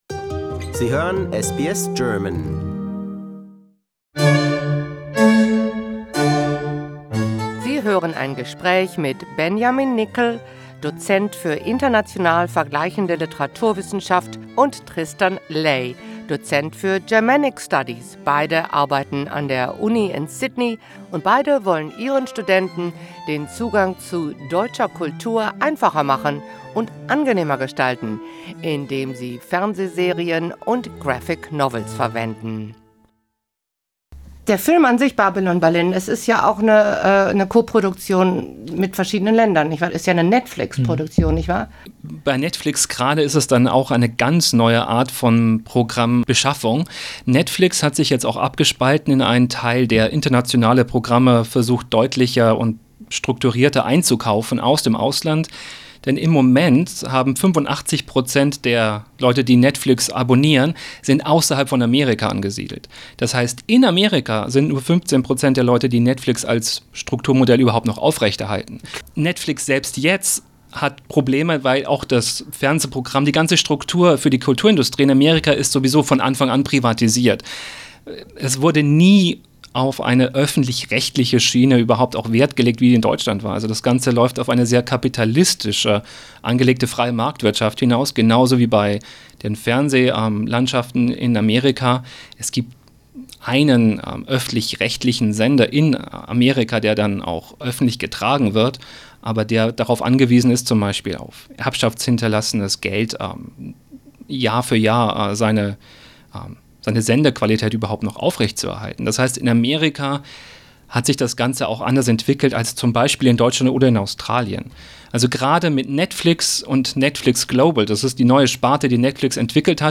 2nd part of the interview about German Noir as part of the study of German language and culture. Could this make German classes more attractive, contemporary and cross-cultural?
They were in our studio and talked about the topic.